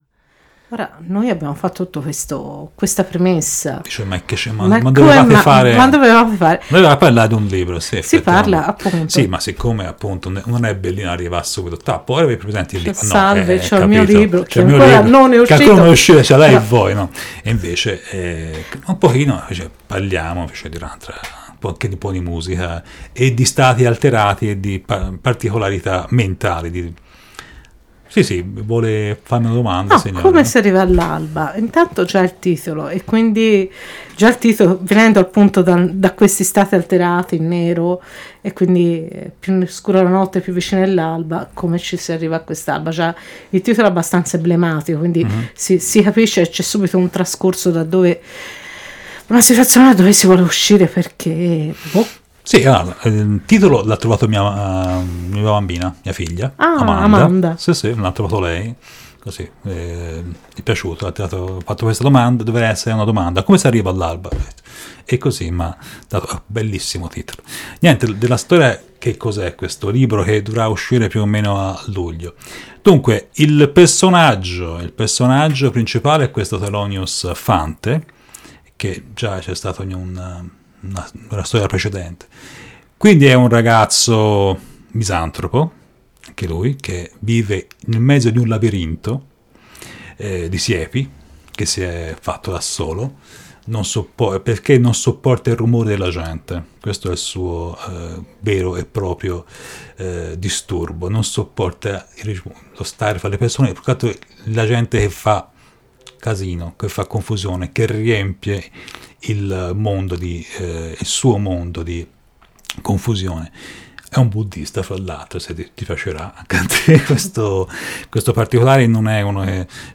Un viaggio per capire attraverso la musica la storia, i personaggi e il mistero che si cela dietro questo nuovo libro.